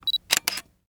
camera3.mp3